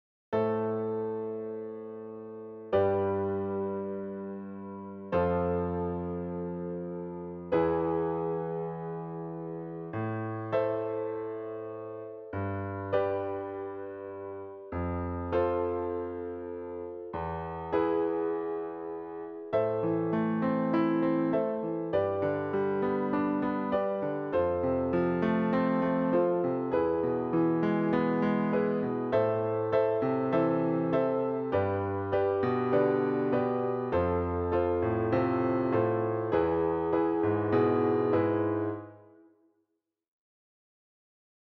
Übungsbeispiel die Spanische Kadenz
Am – G – F – E (oder E7)
Beispiel in A-Moll: